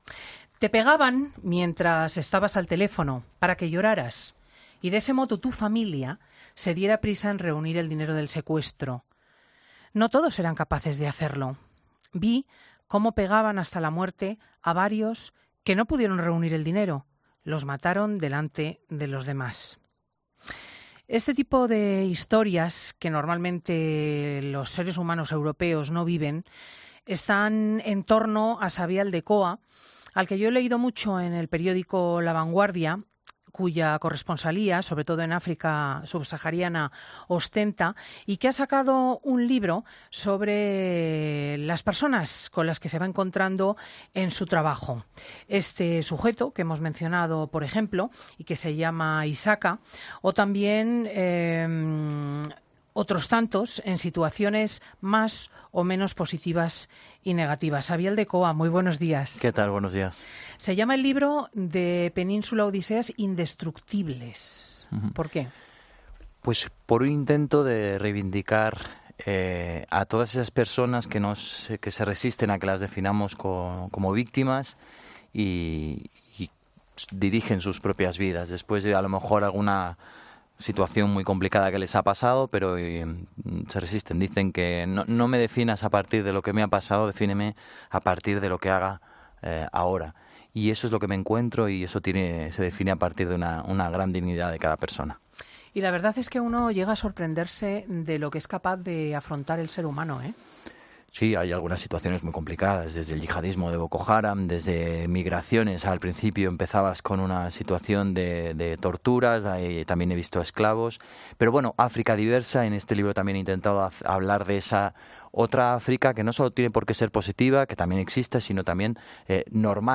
AUDIO: Xavier Aldekoa conoce bien África. Lo plasma en su nuevo libro, 'Indestructibles' que ha presentado con Cristina López Schlichting